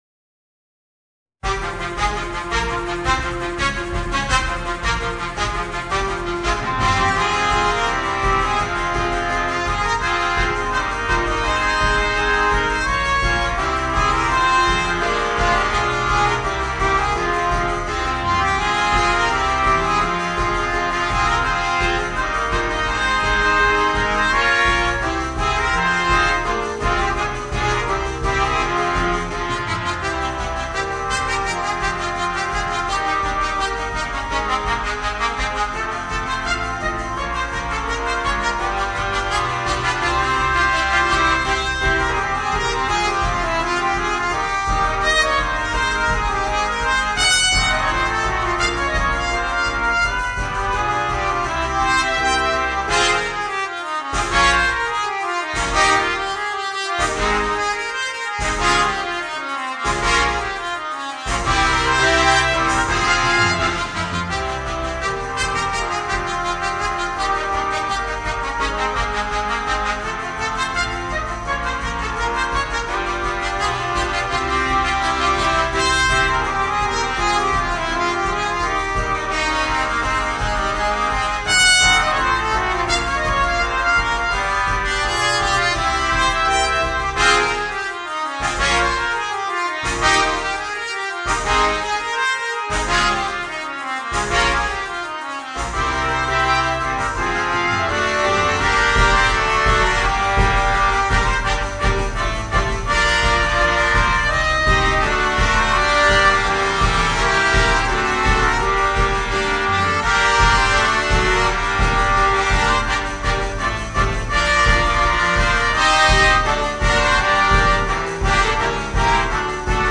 Voicing: 5 Trumpets